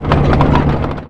tank-engine-load-rotation-1.ogg